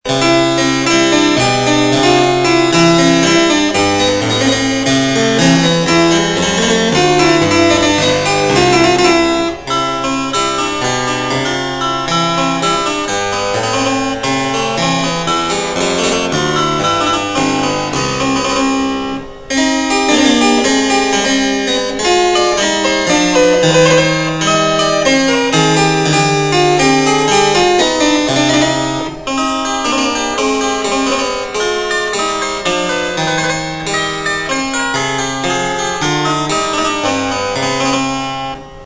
clavecin français
J'ai ajouté un jeu de luth et la "transposition".
Il mesure 2400 mm de long et a deux jeux de 8 pieds et un de 4.